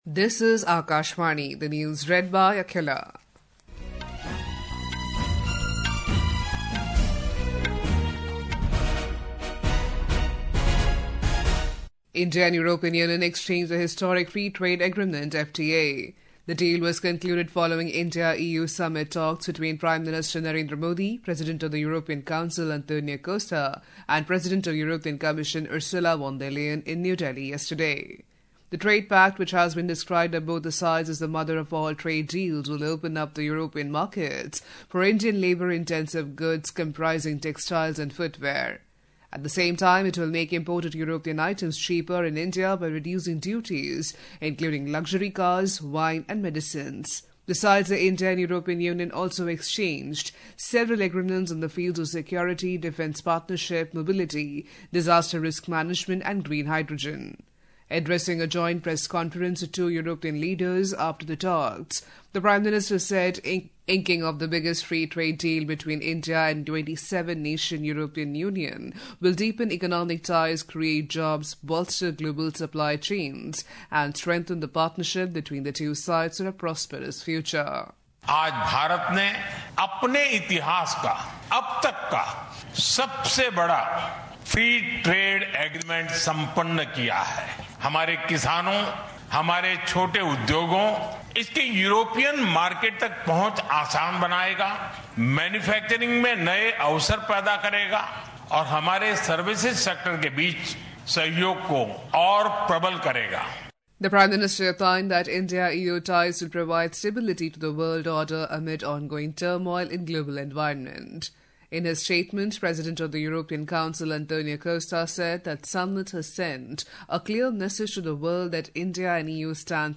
Hourly News